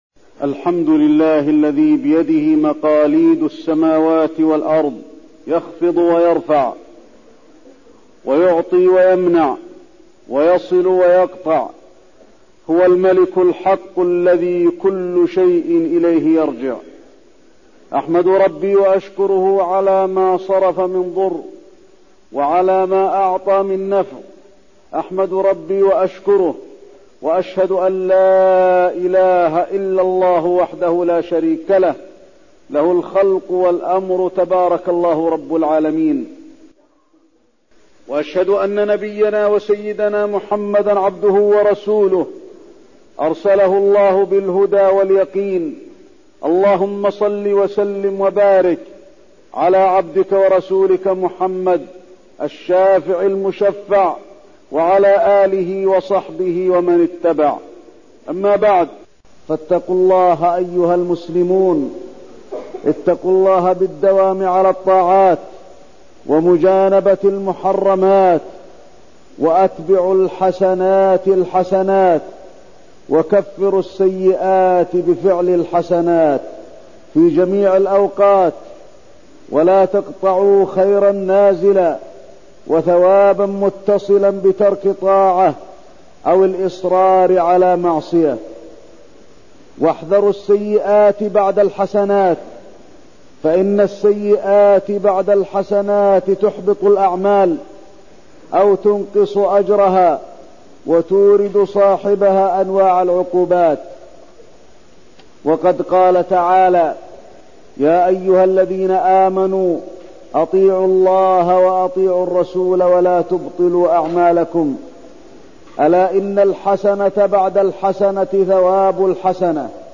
تاريخ النشر ٣ شوال ١٤١٣ هـ المكان: المسجد النبوي الشيخ: فضيلة الشيخ د. علي بن عبدالرحمن الحذيفي فضيلة الشيخ د. علي بن عبدالرحمن الحذيفي خروج شهر رمضان والحث على مواصلة العمل بعده